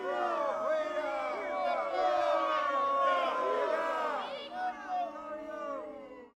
sfx_crowd_boo.ogg